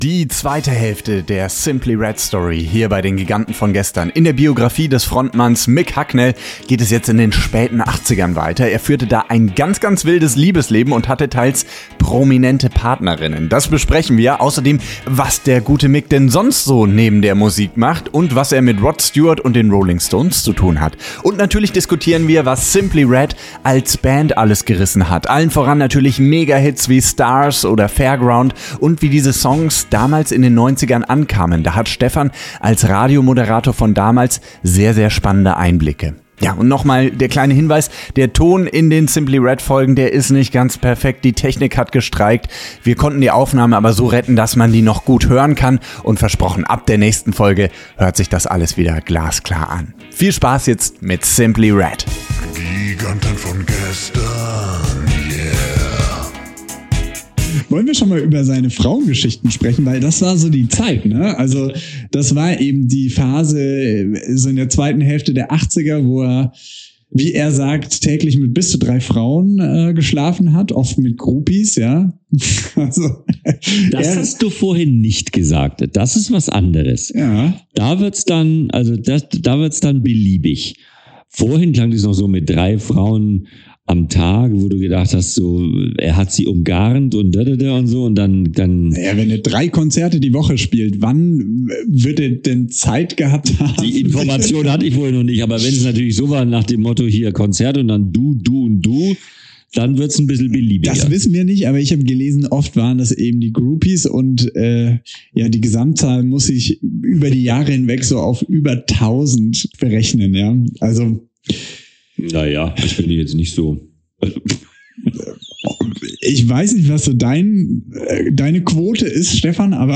Hinweis: Die Audioqualität ist nicht ganz optimal, weil die Technik streikte.